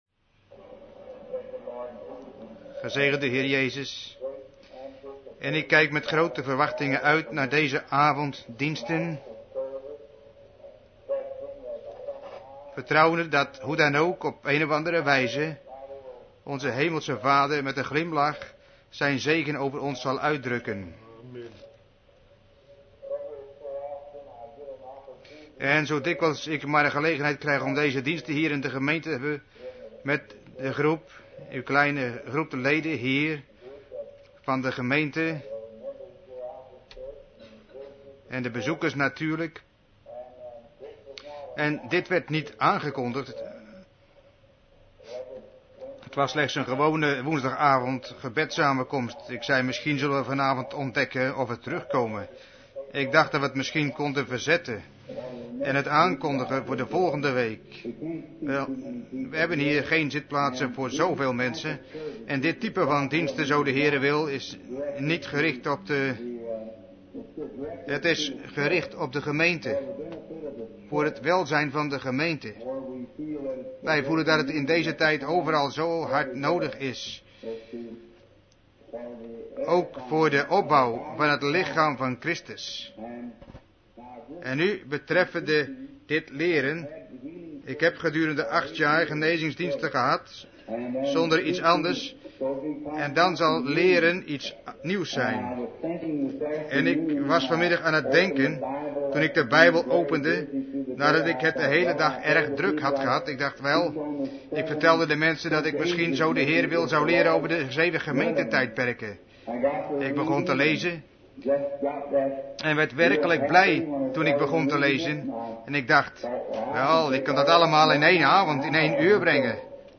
Vertaalde prediking "The seven church ages" door William Marrion Branham te Branham Tabernacle, Jeffersonville, Indiana, USA, op woensdag 12 mei 1954